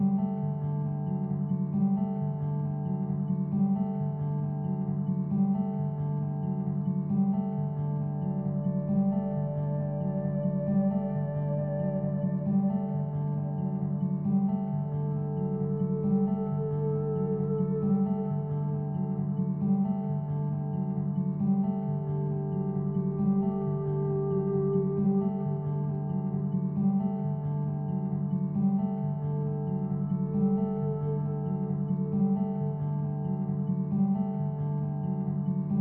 Here is the sketch using that sample.